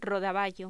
Locución: Rodaballo
voz
Sonidos: Voz humana